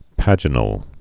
(păjə-nəl)